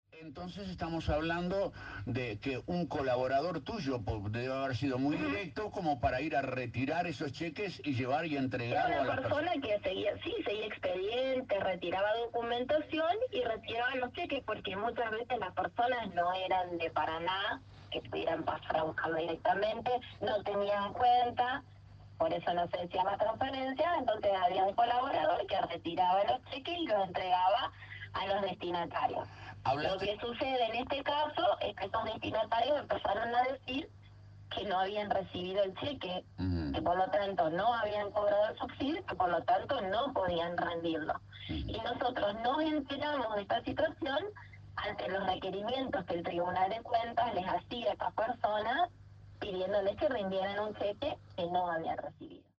La vicegobernadora Laura Stratta eligió Radio LT 39 de Victoria para intentar minimizar el escándalo de los subsidios truchos que la tiene como protagonista y que ha generado un enorme escándalo en la ciudad de Victoria y en toda la Provincia.
Entrevista-a-Stratta-6.mp3